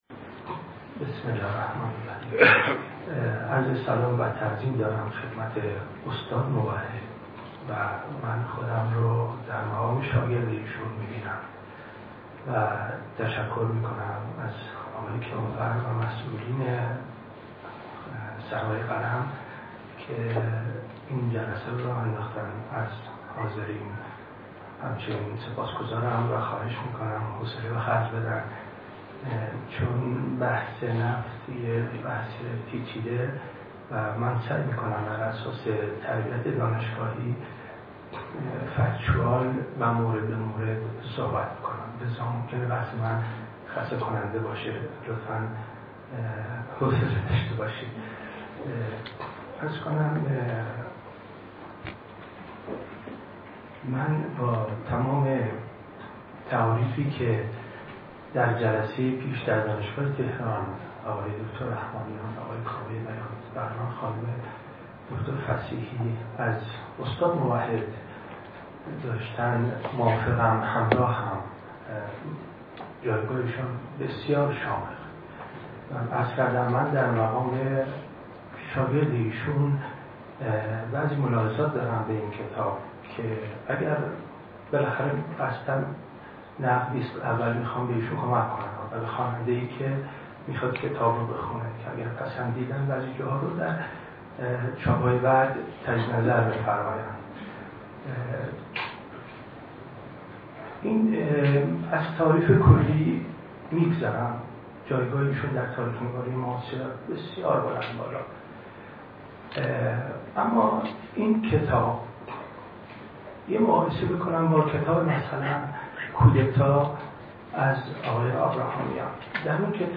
سخنرانی
در سرای اهل قلم برگزار کرد